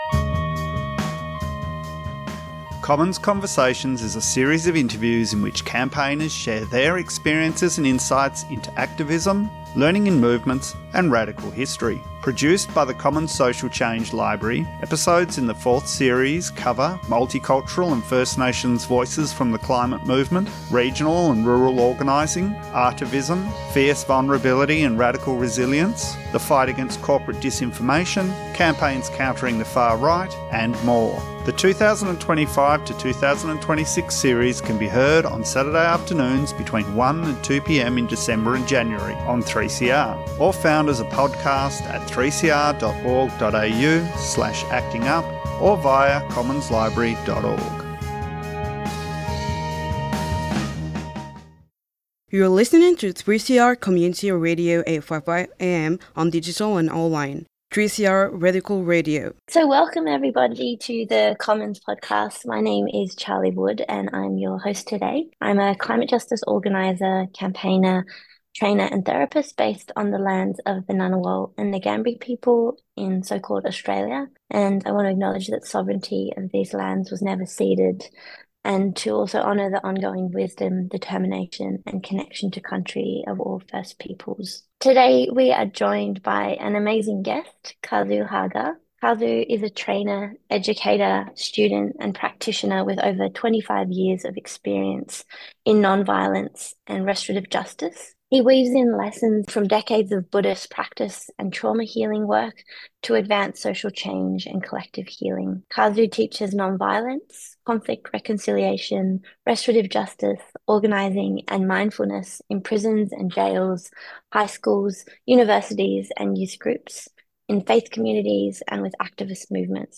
This episode features talks that explore the intersections of poverty, homelessness and trauma, and how these experiences often include an interception of time in prison.